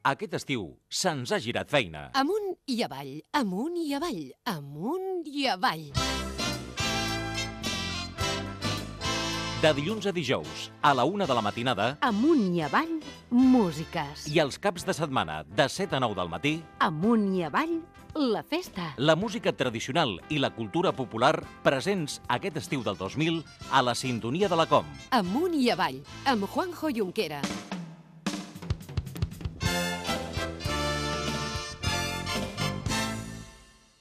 eeeef0a26ced0b2872dc2096c0ebb0a887aaf55e.mp3 Títol COM Ràdio Emissora COM Ràdio Barcelona Cadena COM Ràdio Titularitat Pública nacional Nom programa Amunt i avall Descripció Promoció de l'edició d'estiu del programa.